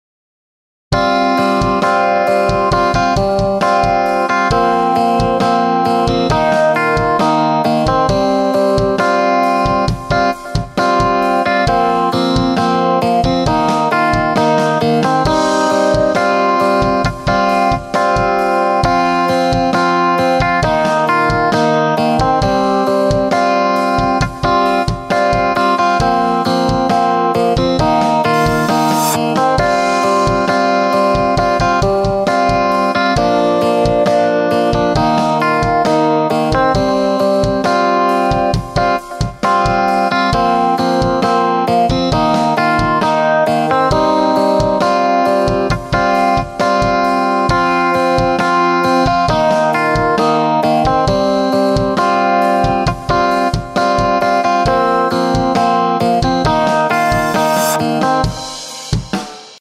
Phase Clean